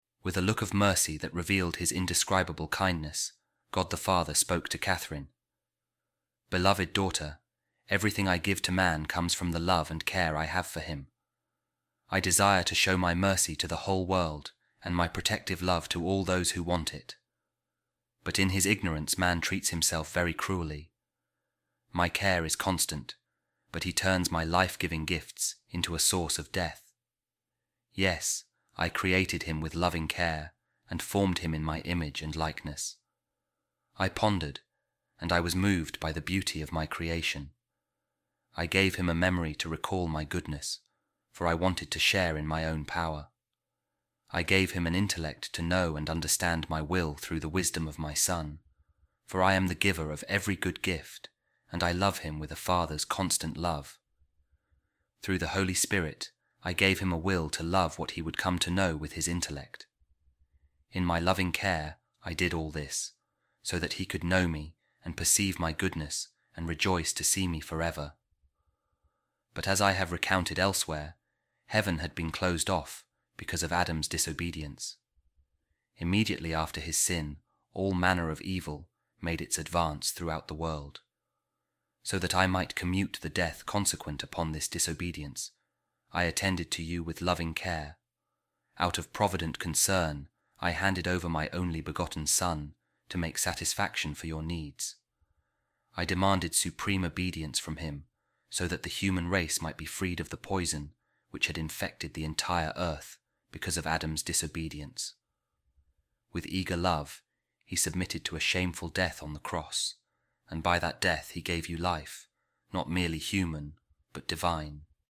A Reading From The Dialogue Of Saint Catherine Of Siena On Divine Providence | How Good, How Delightful Is Your Spirit, Lord, Dwelling In All Men